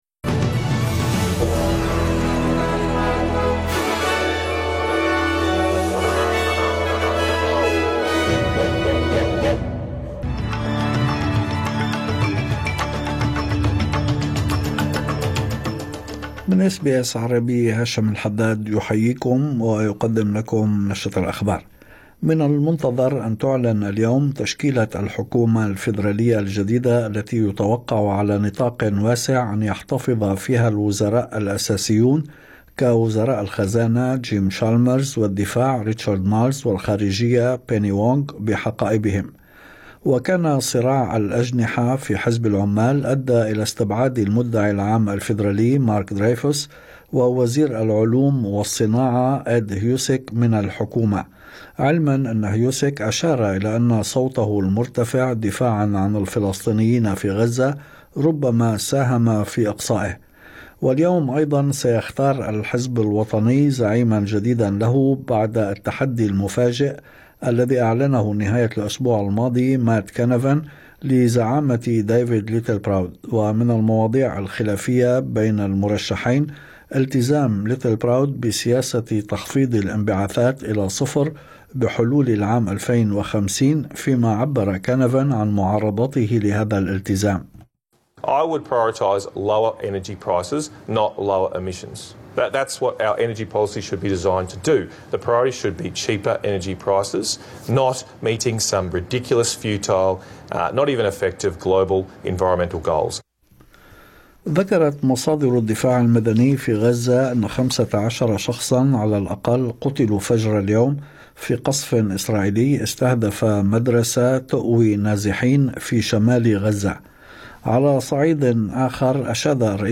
نشرة أخبار الظهيرة 12/5/2025